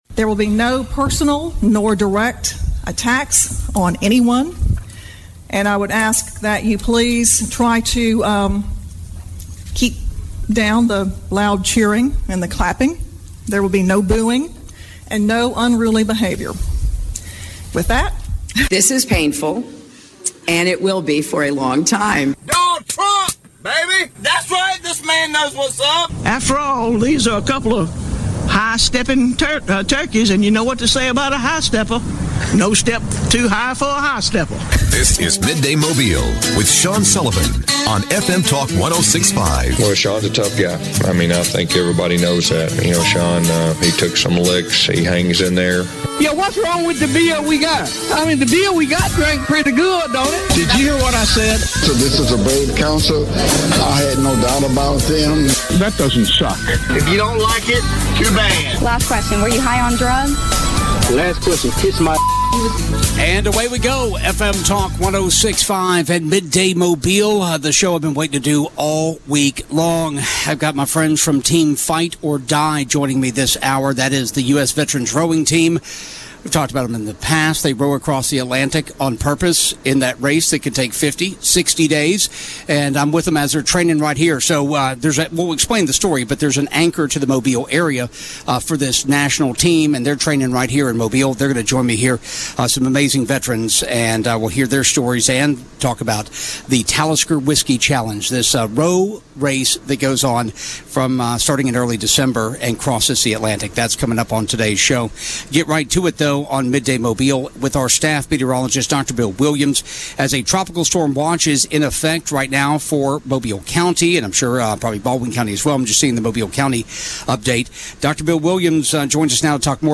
Midday Mobile - Live with Fight Oar Die at Buccaneer Yacht Club - August 27 2021